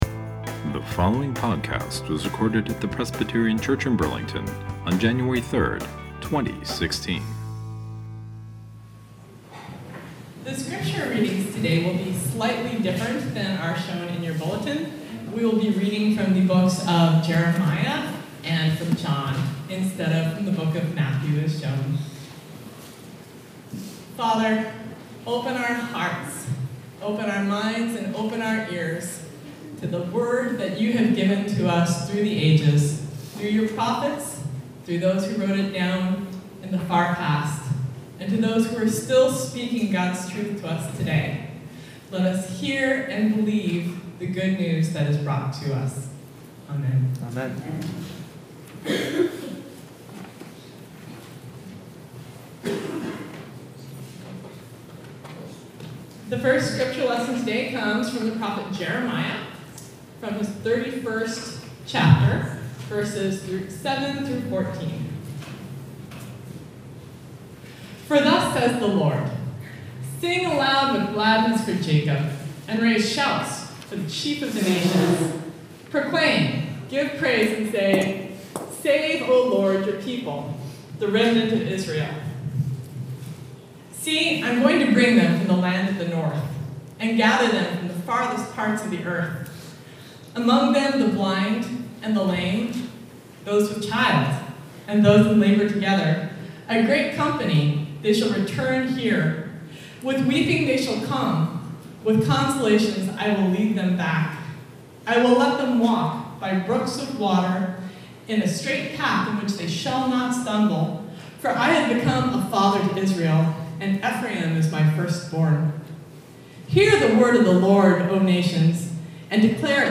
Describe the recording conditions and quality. And the Darkness Shall Not Overcome – Presbyterian Church in Burlington